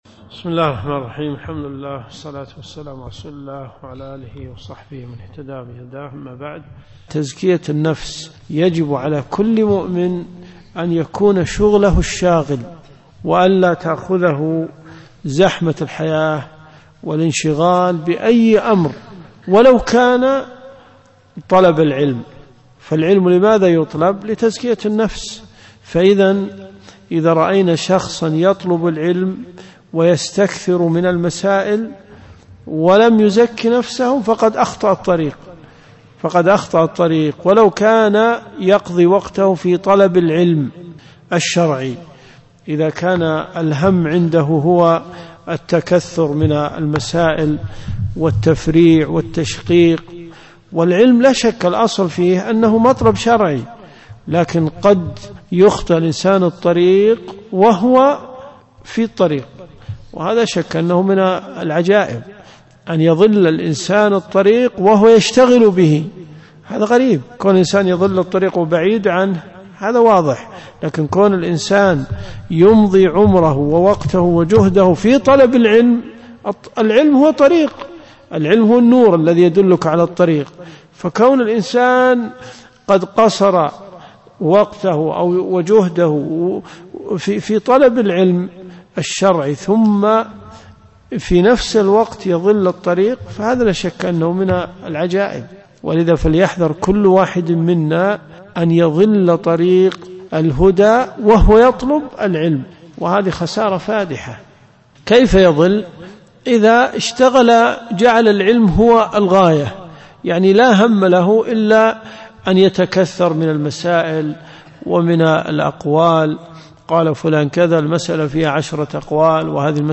دروس صوتيه ومرئية تقام في جامع الحمدان بالرياض
بدء الدرس في الدقيقة . 9.20 .
جامع البلوي